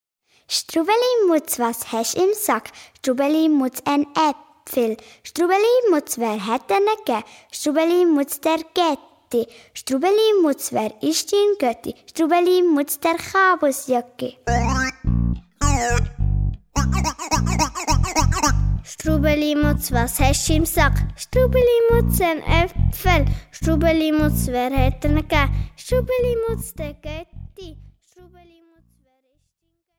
Besetzung: Gesang